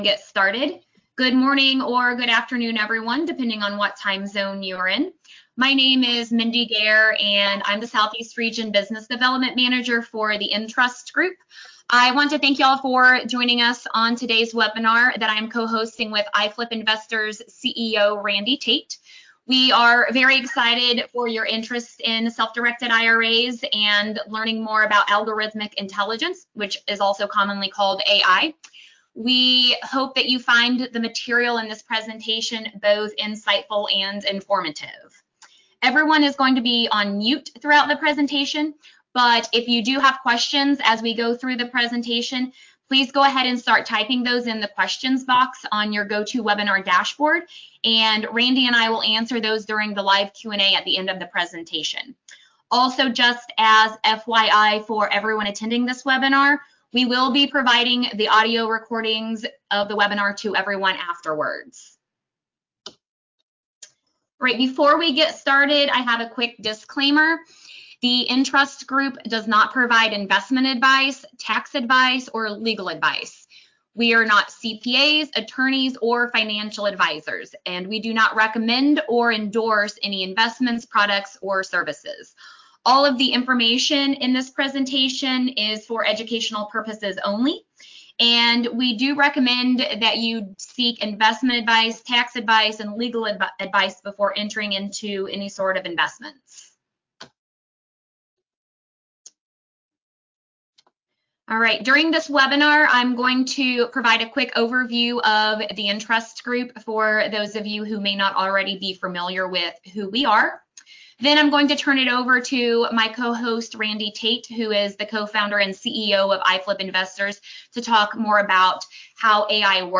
In this webinar replay, we discuss the opportunities and risks of using AI to invest with a Self-Directed IRA.